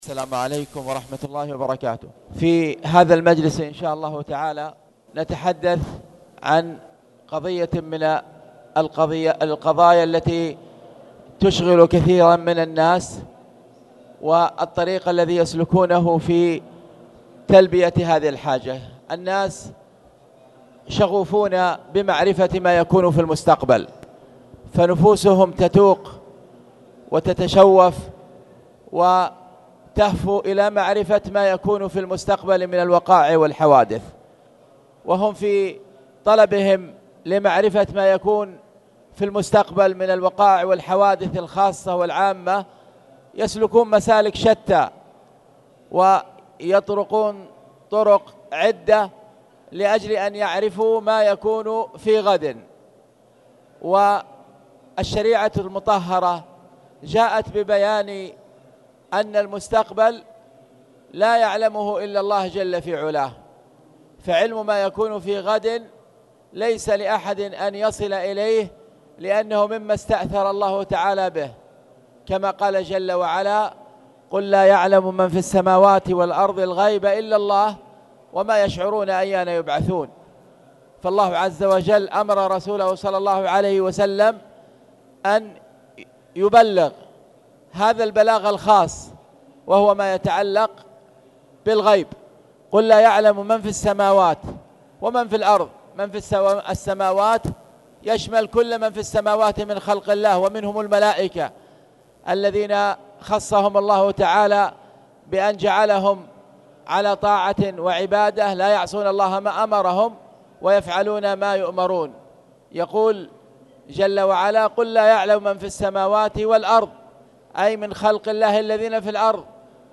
تاريخ النشر ٦ رجب ١٤٣٨ هـ المكان: المسجد الحرام الشيخ